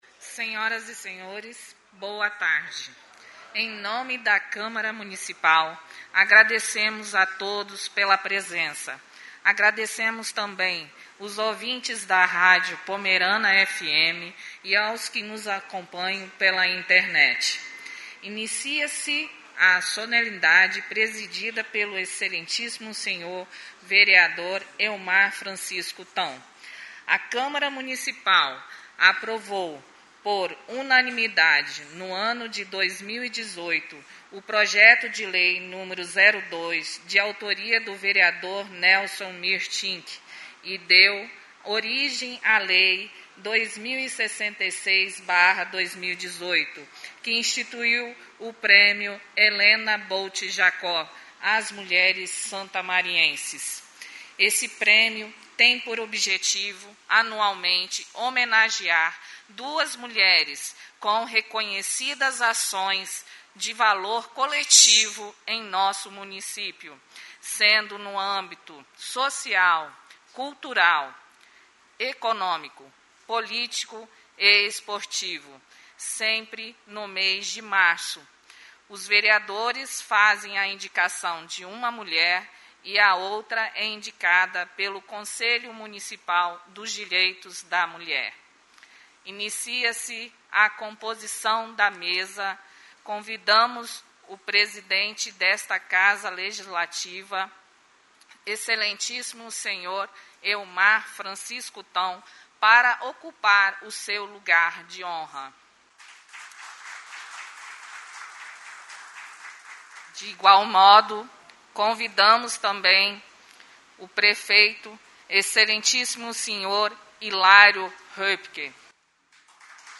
SESSÃO SOLENE Nº 17/2022